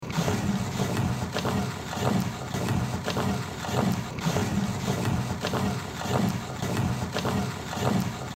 Звуки мясорубки
Звук ручної мясорубки